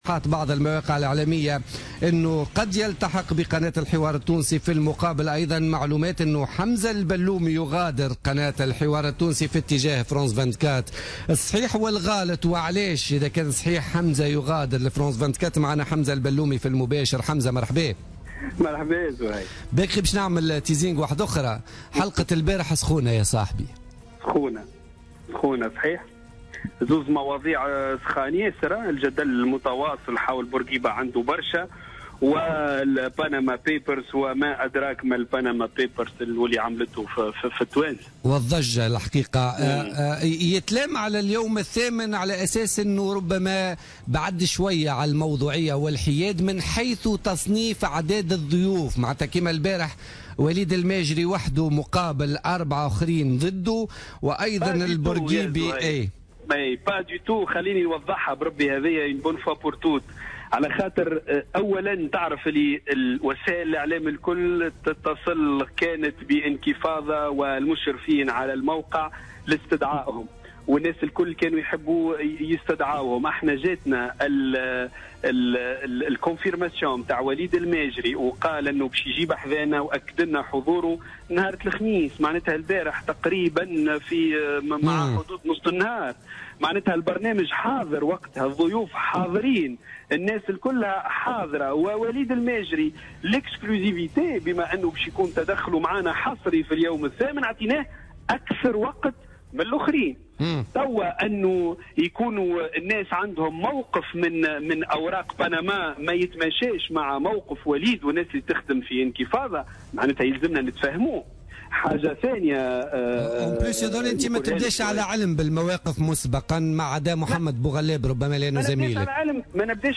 في مداخلة له في بوليتيكا